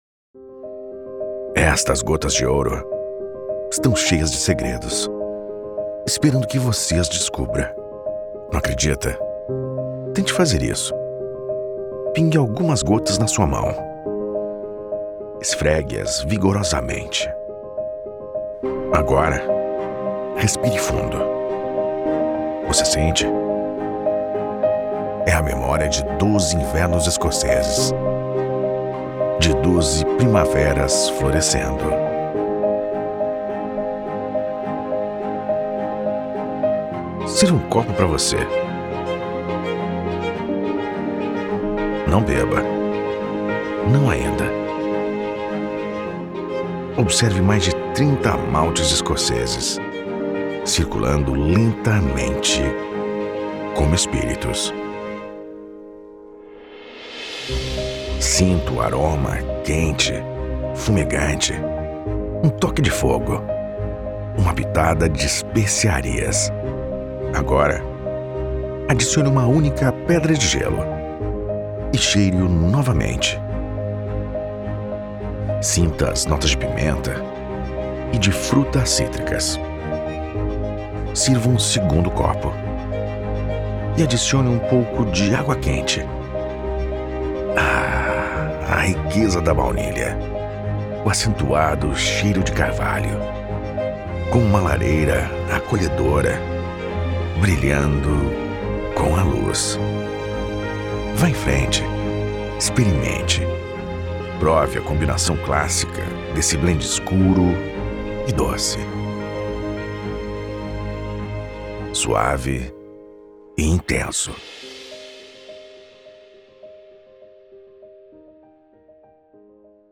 Male
Confident, Cool, Engaging, Friendly, Gravitas, Natural, Versatile, Young
Brazilian Portuguese, Sao Paulo, Parana.
His voice is youthful and fresh, radiating an energy that adds an extra dimension to his work. With his clear and articulate delivery, he delivers scripts with precision and clarity, ensuring that every word is heard and understood by his audience.
Supremacy 1914 Voice Over Portuguese.mp3
Microphone: Sennheiser 416, Neumann Tlm 49